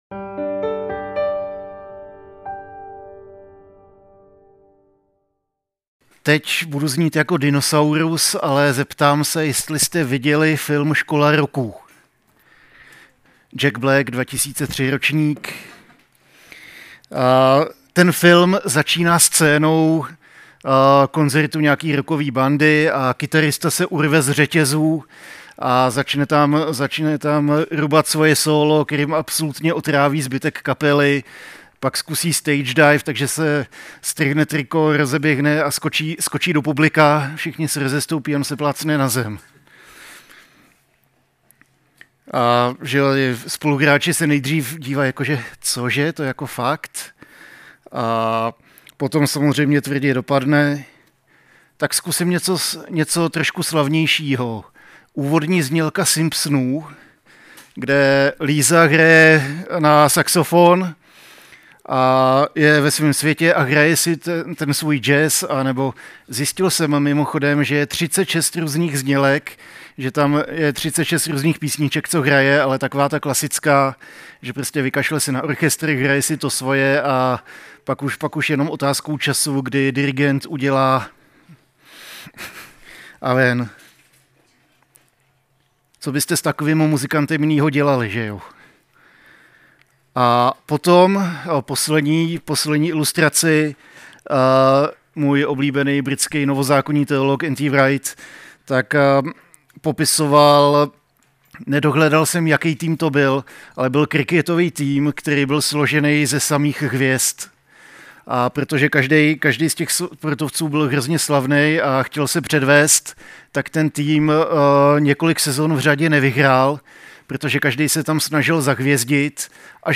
A kázání od série "Církev pod lupou."